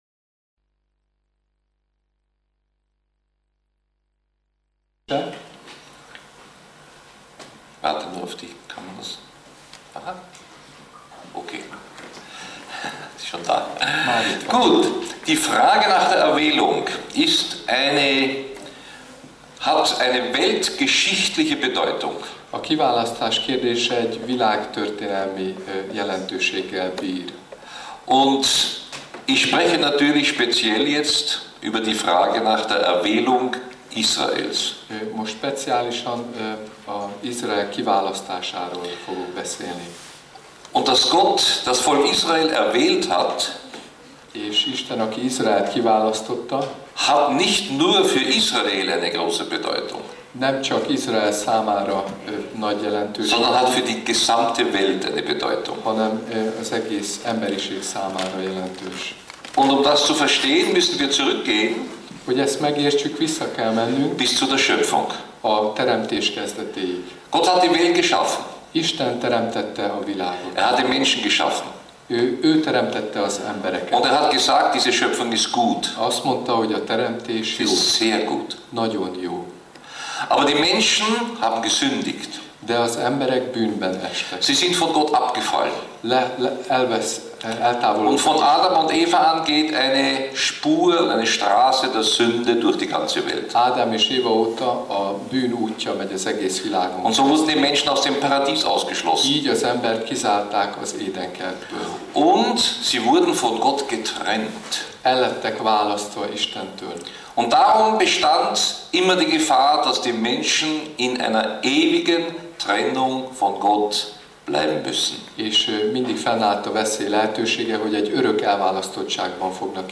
soproni előadása